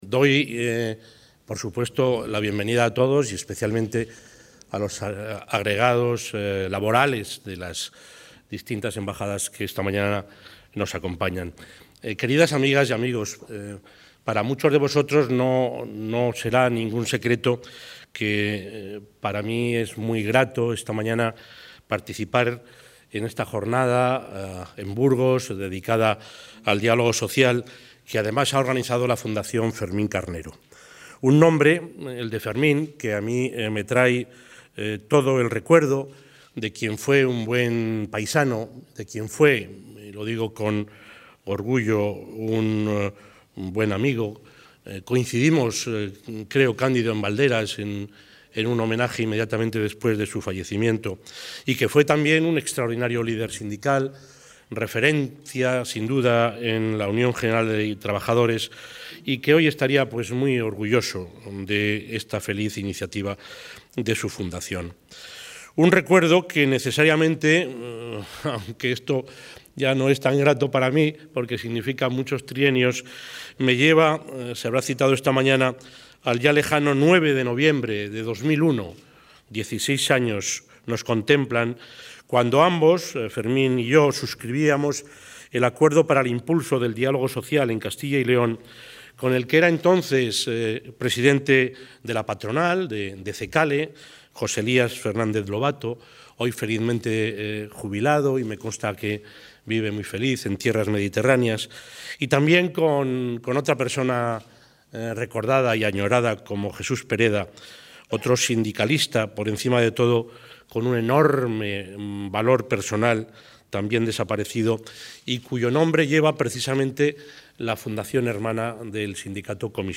Audio presidente.
El presidente de la Junta de Castilla y León, Juan Vicente Herrera, ha clausurado esta mañana la Jornada ‘Diálogo Social, instrumento de cohesión’, organizada por la Fundación Fermín Carnero, donde ha animado a continuar avanzando hacia la internacionalización de un modelo de hacer las cosas que constituye una de las señas de identidad de la Comunidad y uno de los máximos ejemplos de la Democracia participativa.